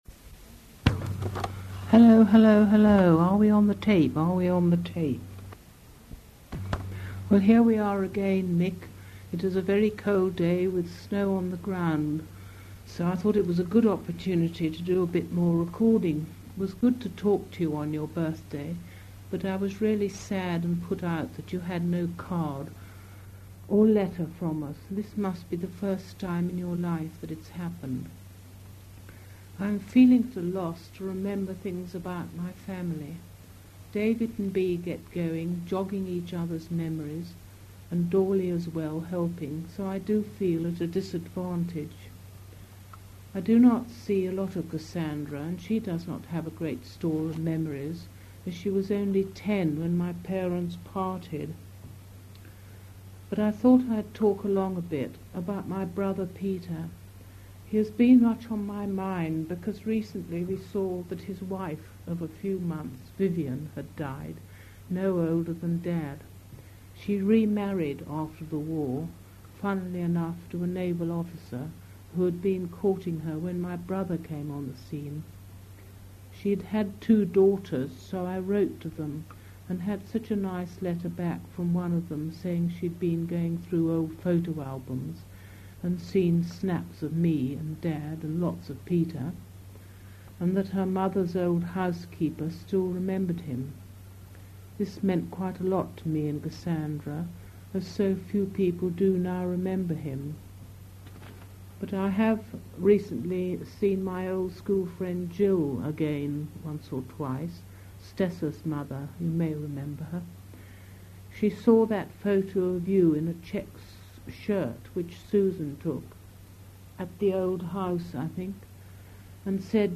Interview audio in new tab